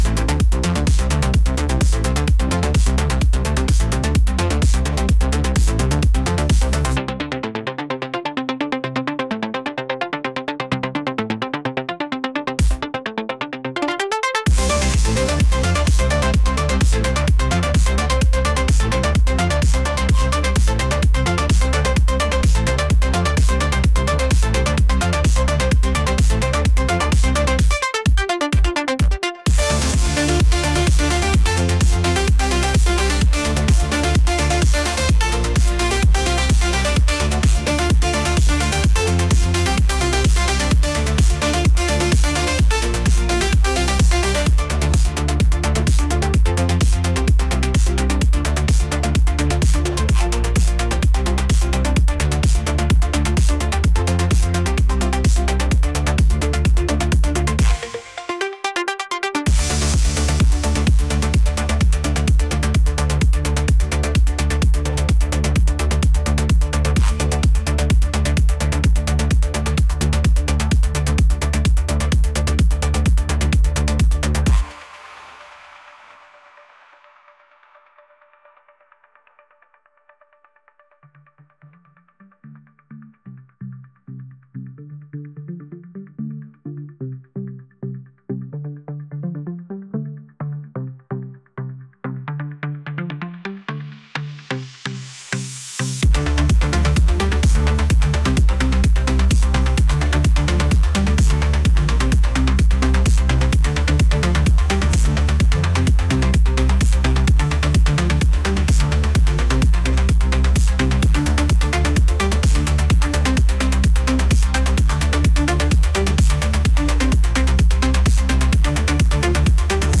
electronic | energetic